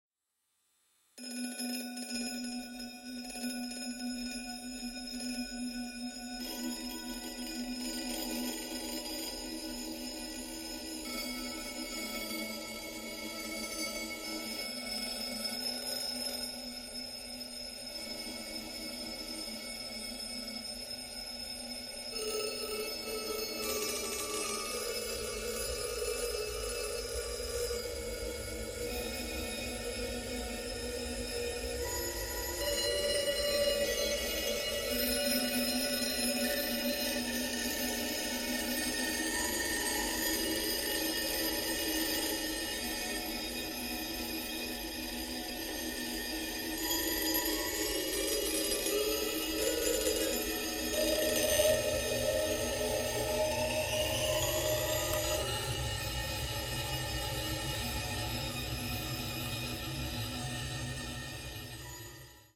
toy piano